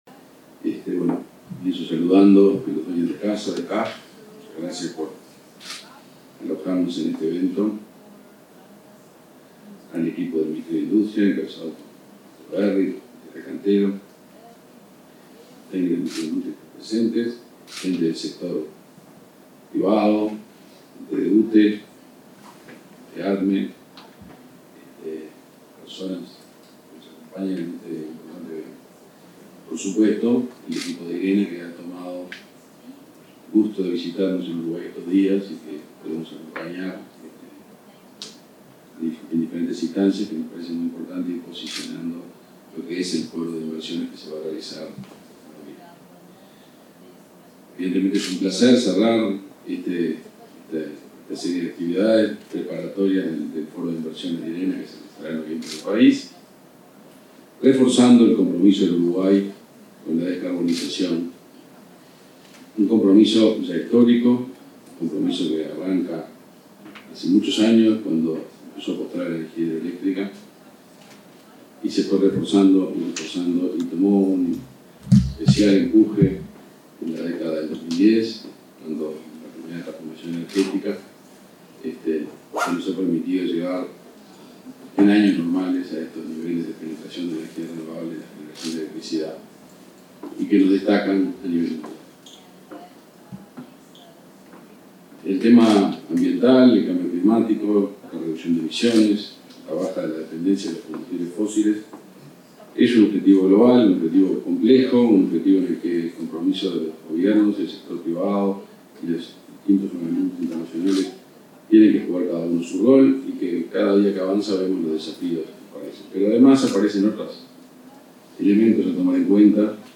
Palabras del ministro de Industria, Omar Paganini
Palabras del ministro de Industria, Omar Paganini 15/03/2023 Compartir Facebook Twitter Copiar enlace WhatsApp LinkedIn Este miércoles 15, el Ministerio de Industria, Energía y Minería (MIEM) y la Agencia Internacional de Energías Renovables (Irena) lanzaron el Latin American Investment Forum en Uruguay. El titular de la cartera, Omar Paganini, participó en el evento.